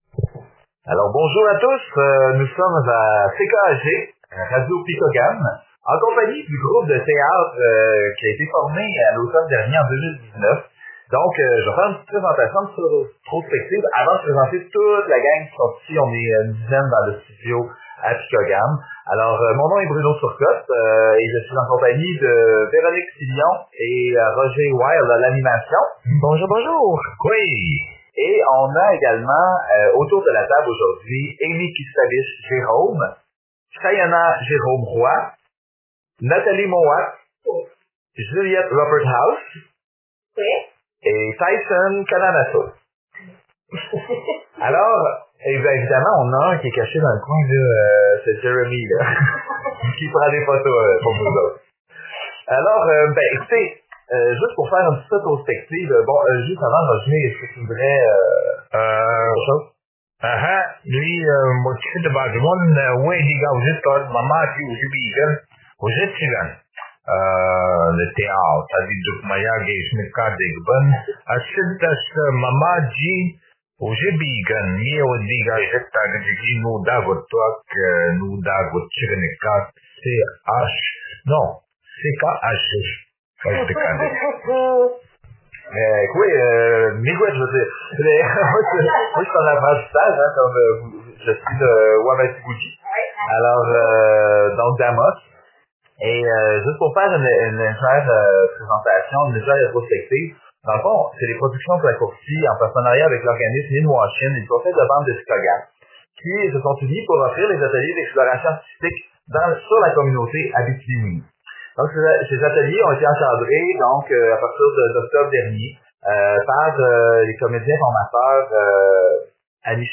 Fait partie de Entrevue avec l'équipe de théâtre